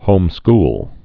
(hōmskl)